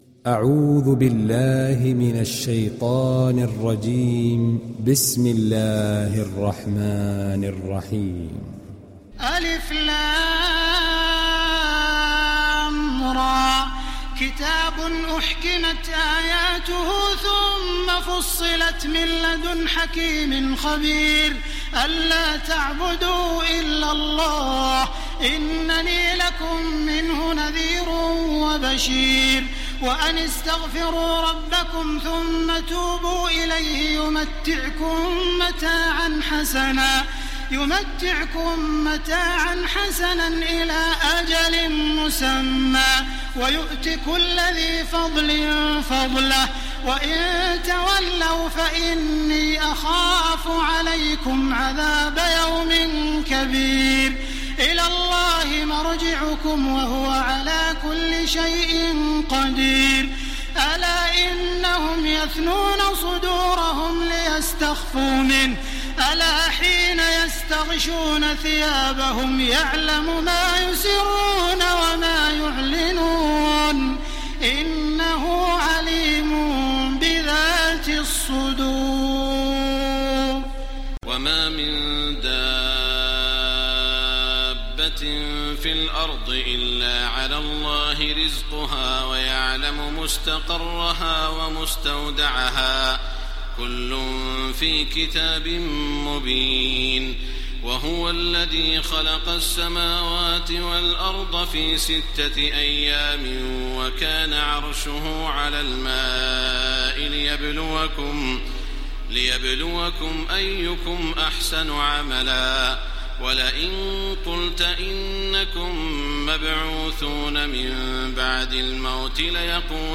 Télécharger Sourate Hud Taraweeh Makkah 1430